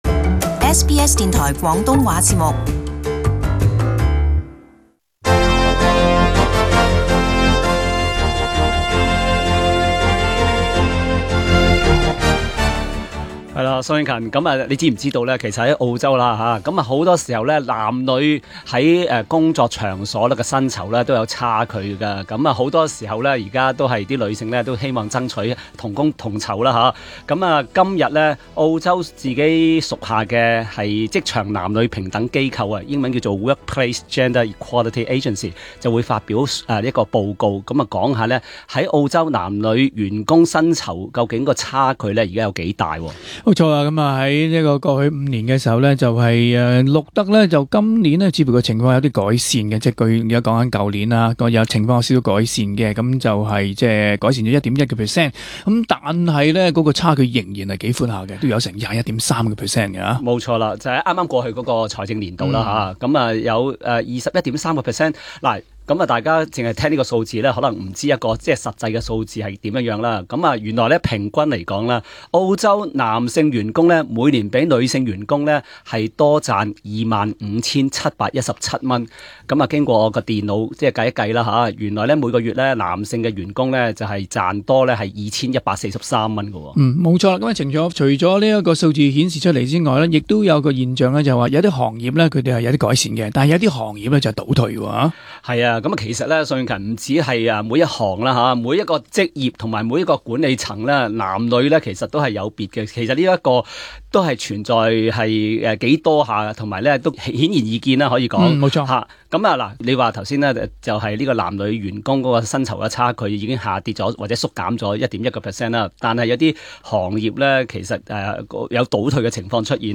【時事報導】 男女工資差距漸窄